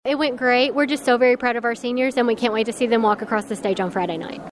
The senior class of Caldwell County High School received their graduation cords and stoles Monday morning in a special presentation in the high school Fine Arts Building.